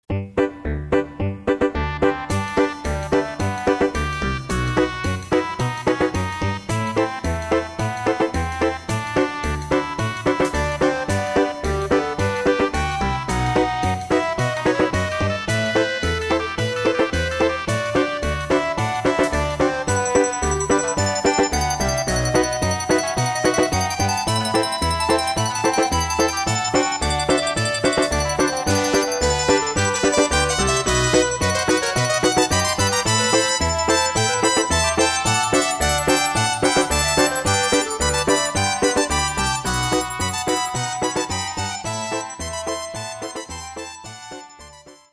Musique électronique
comédie musicale pour enfants